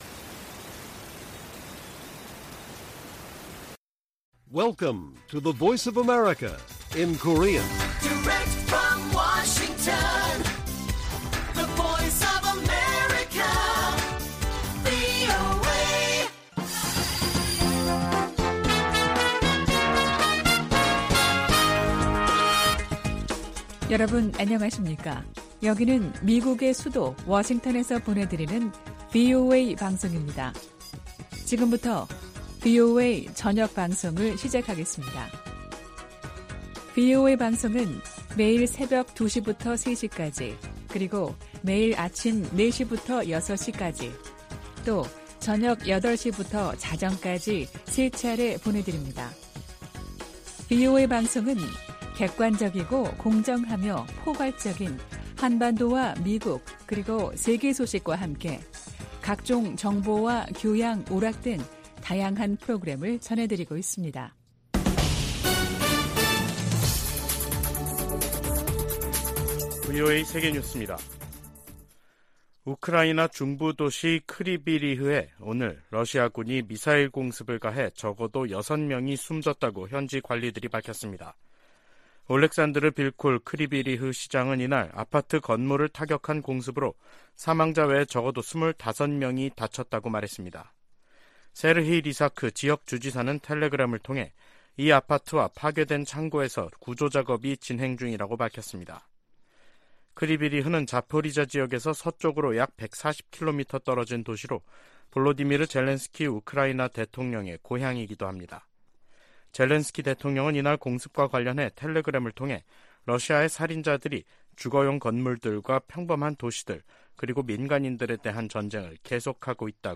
VOA 한국어 간판 뉴스 프로그램 '뉴스 투데이', 2023년 6월 13일 1부 방송입니다. 미국의 북핵 수석 대표는 워싱턴에서 한국의 북핵 수석대표와 회담한 후 북한의 추가 도발에 독자제재로 대응할 것이라는 입장을 밝혔습니다. 북한은 군사정찰위성 추가 발사 의지를 밝히면서도 발사 시한을 미리 공개하지 않겠다는 입장을 보이고 있습니다.